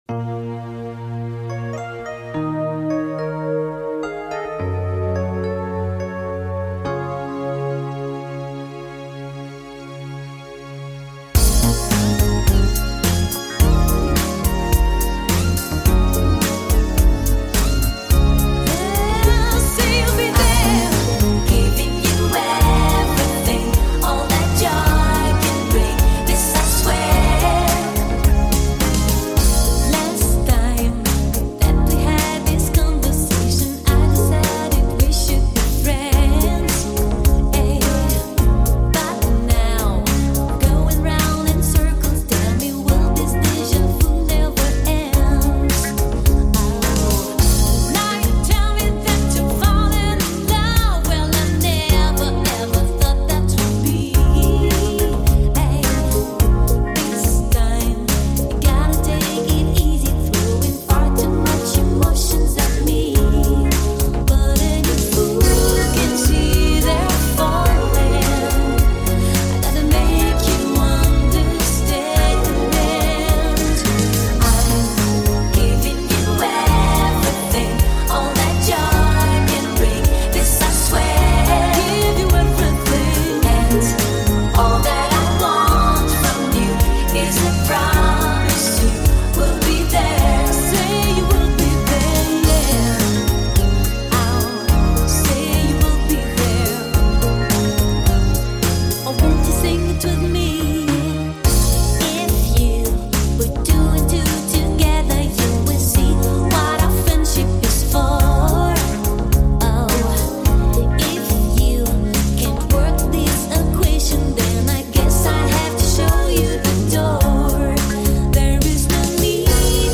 Пару неплохих перепевок, этот вечный хит, хорошо сделано))